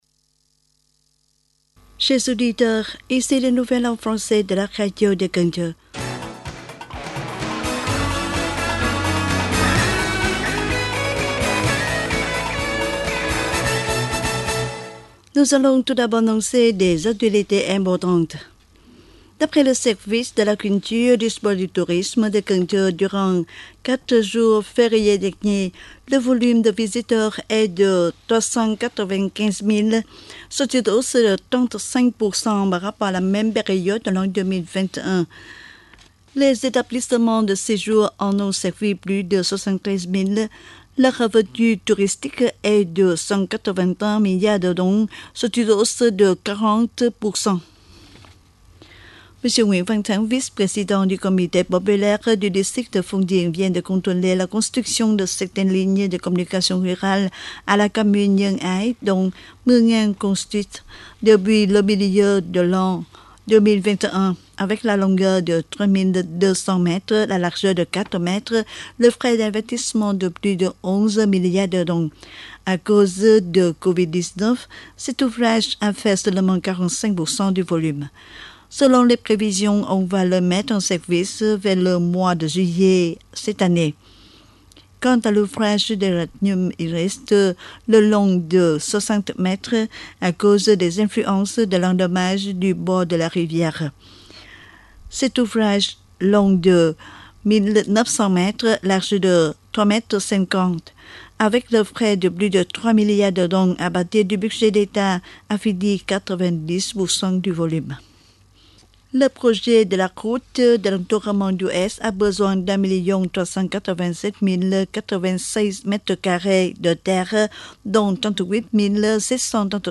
Bản tin tiếng Pháp 3/5/2022